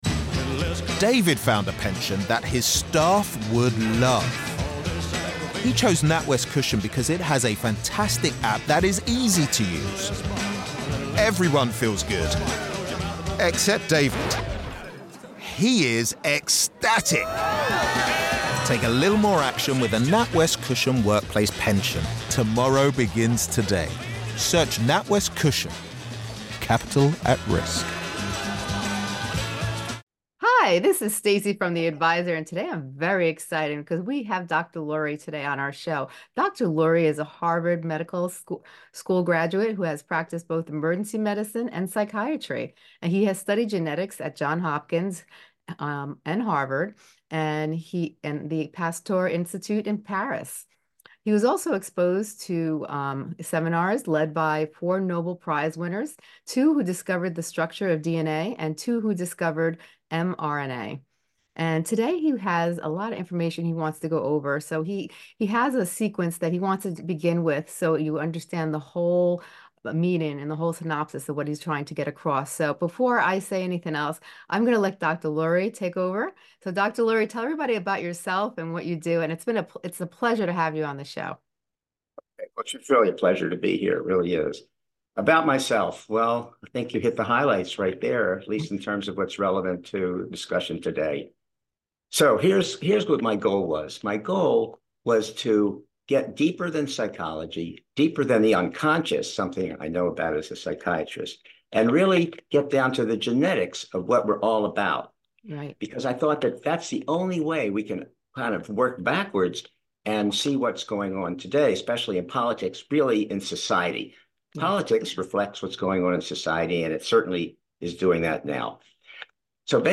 Prepare to be captivated by this enlightening conversation offering actionable solutions to combat political extremism.